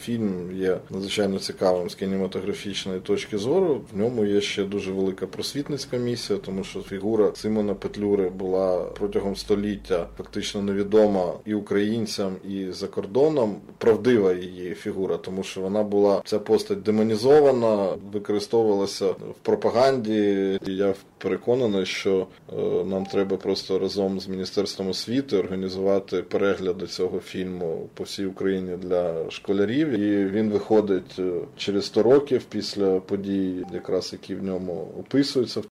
З датою прем'єри продюсери картини ще не визначилися, але голова Держкіно Пилип Іллєнко в ексклюзиному інтерв'ю Українському радіо поділився враженнями від перегляду фінальної версії фільму про одну із знакових постатей української історії.